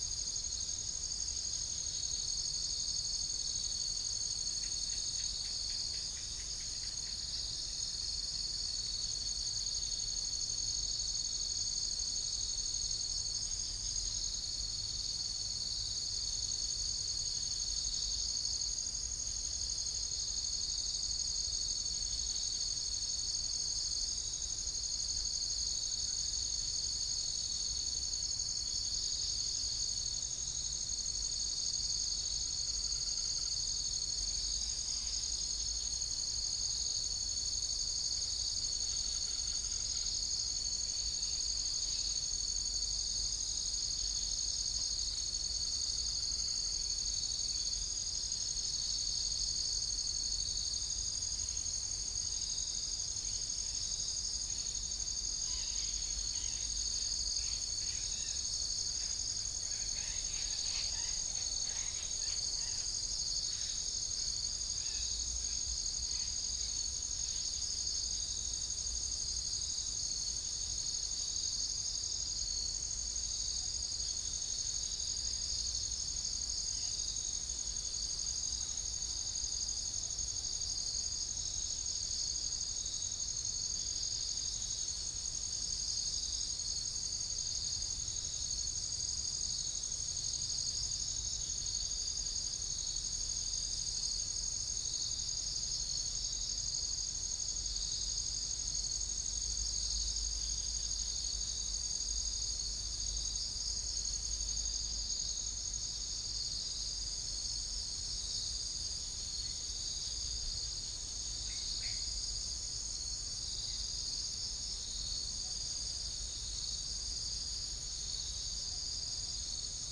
Spilopelia chinensis
Pycnonotus goiavier
Pycnonotus aurigaster
Prinia familiaris